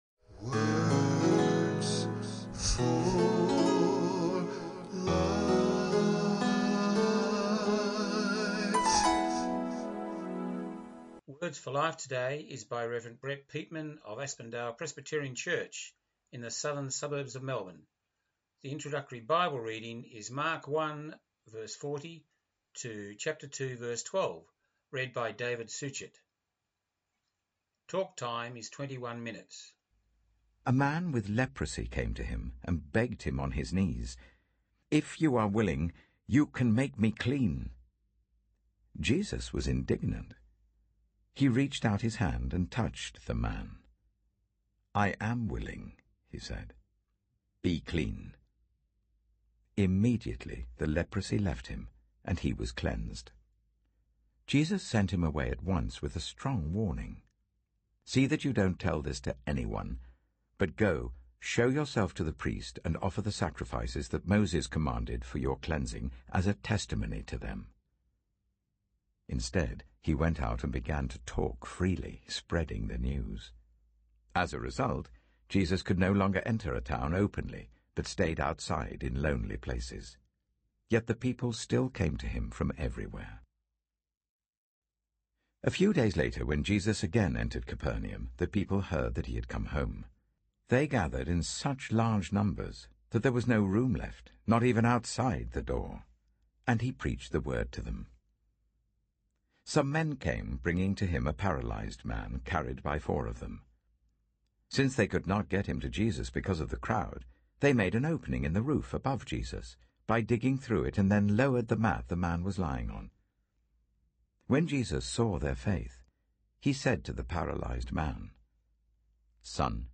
Our Words for Life sermon on Songs of Hope today, Sunday 28nov21,